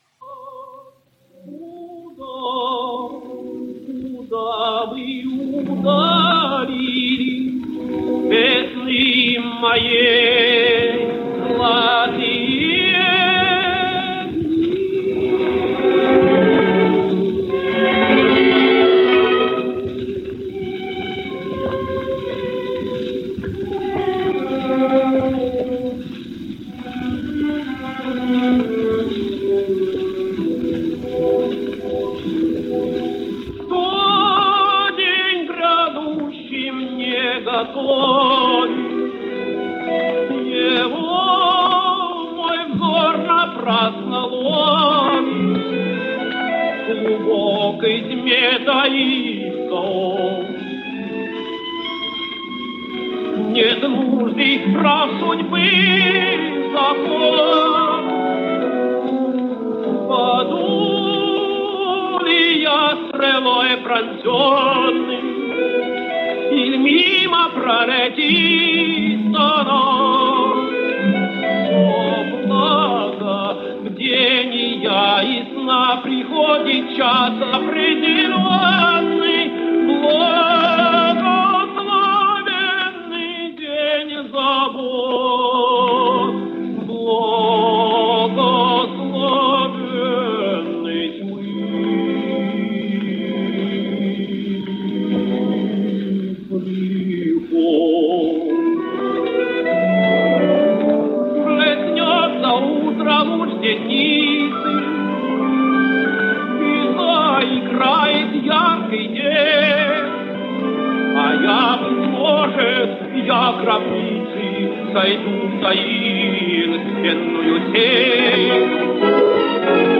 Belarusian Tenor